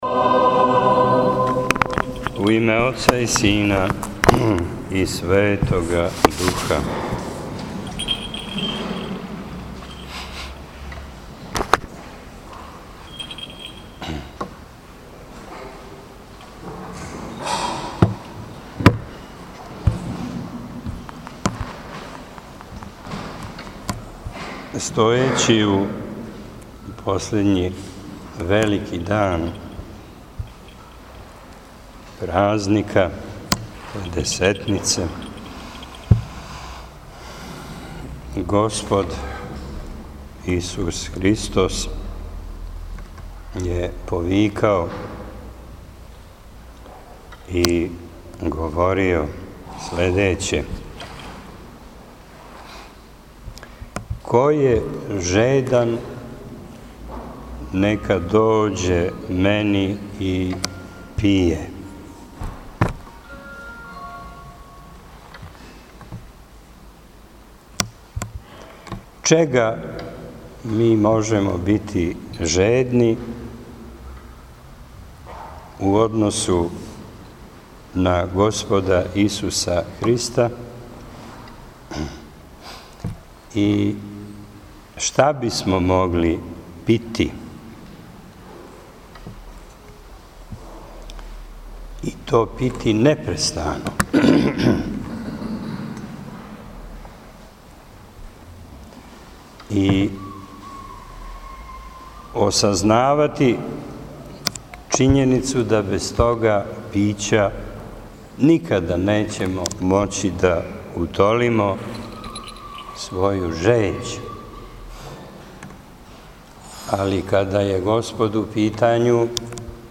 Звучни запис беседе Празник Педесетница је храмовна слава цркве у Трстенику, као и слава Општине Трстеник.